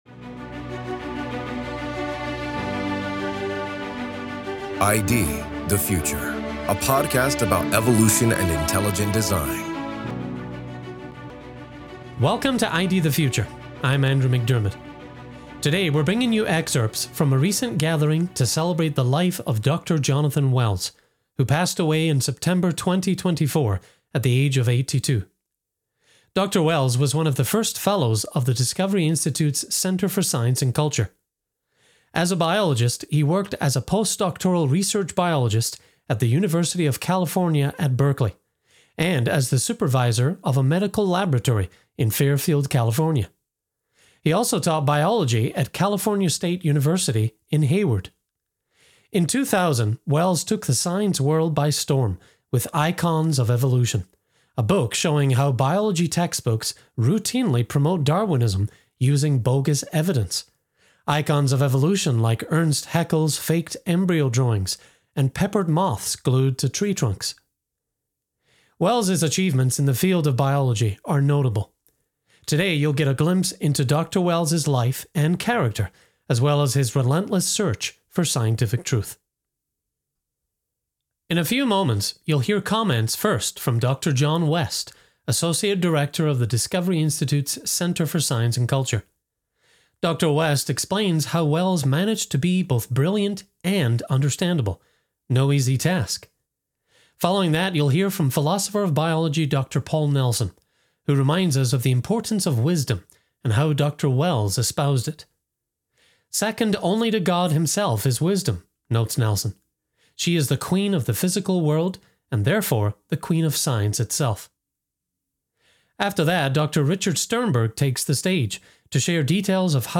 On this ID The Future, we bring you excerpts from a recent gathering to celebrate the life of biologist Dr. Jonathan Wells, who passed away in September 2024 at the age of 82. Dr. Wells was one of the first fellows of Discovery Institute’s Center for Science and Culture.